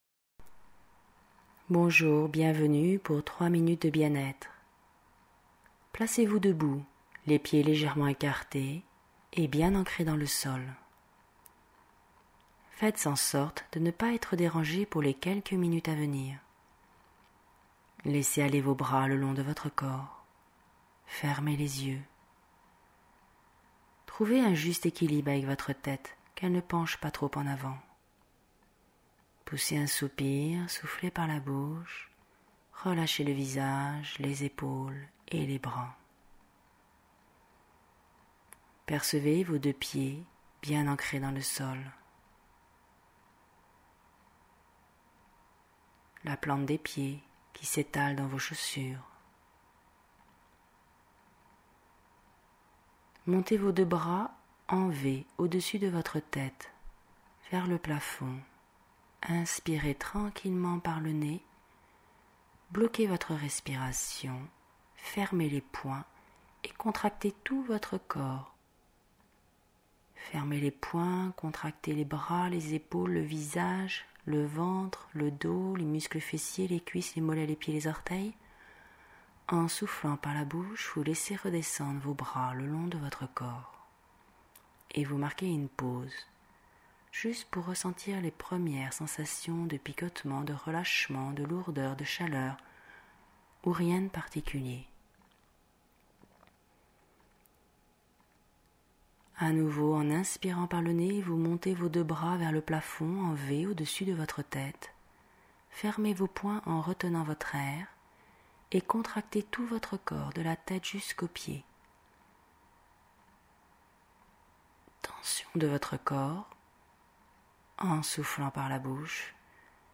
Genre : sophro